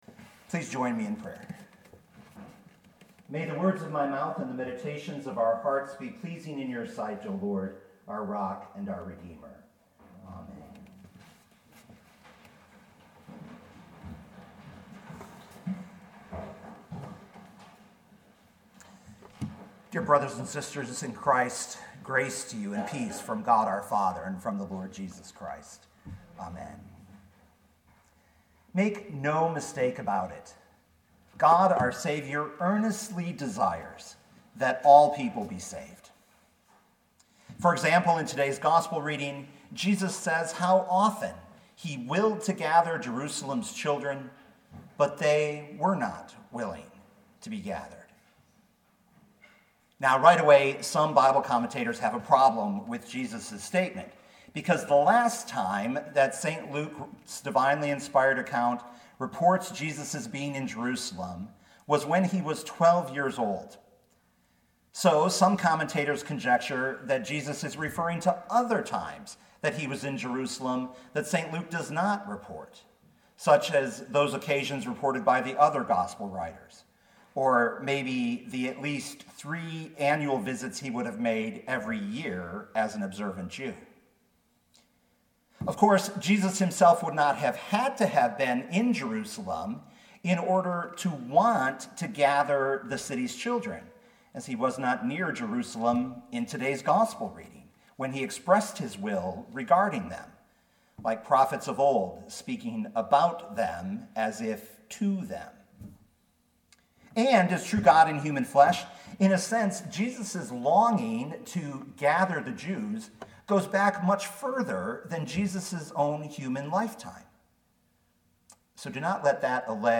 2019 Luke 13:31-35 Listen to the sermon with the player below, or, download the audio.